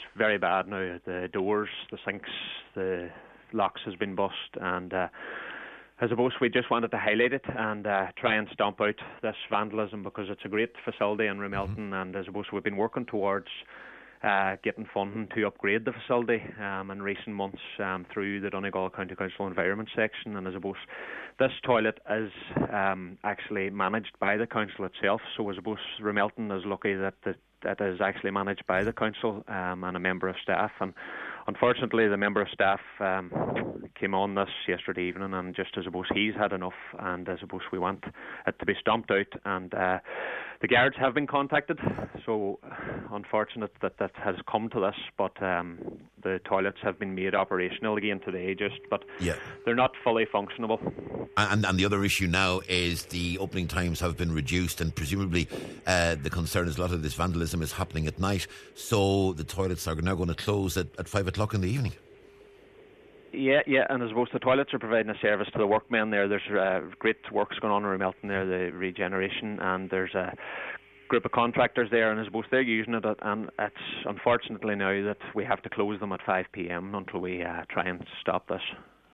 He explains the severity of the damage, which has now impacted the opening hours of the facility: